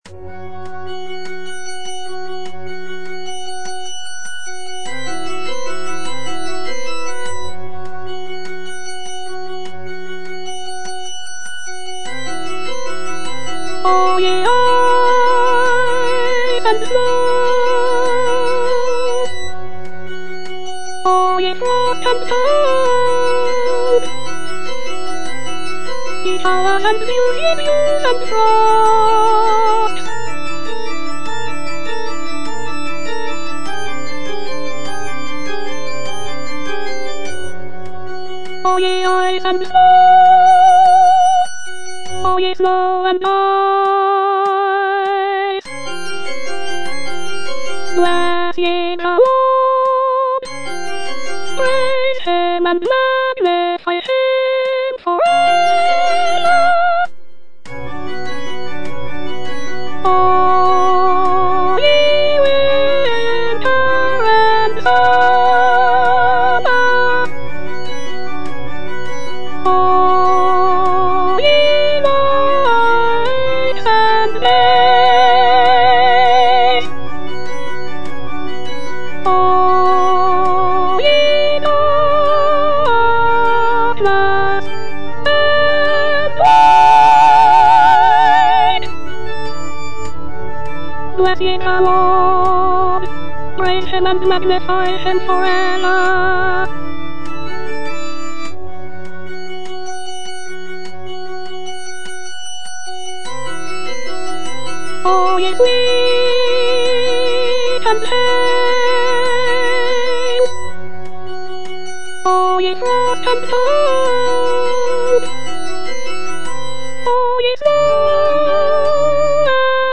choral work